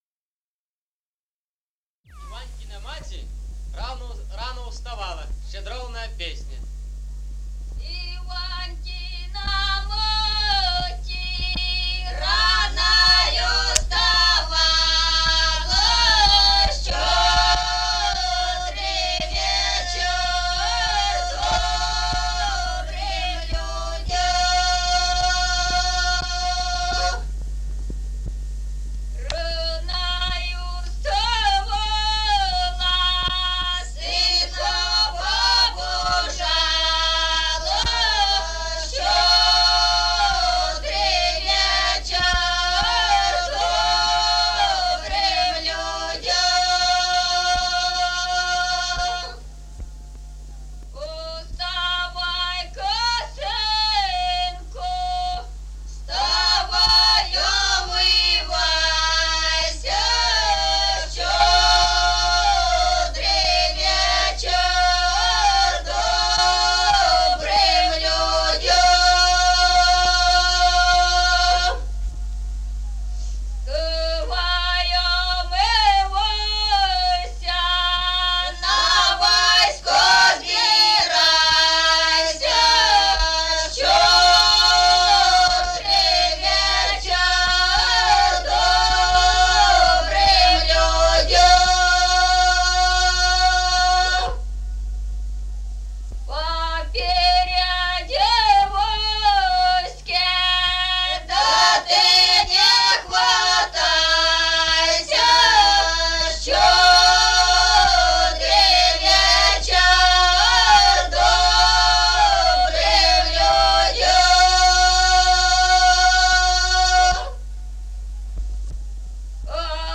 Народные песни Стародубского района «Иванькина мати», новогодняя щедровная.
1953 г., с. Мишковка.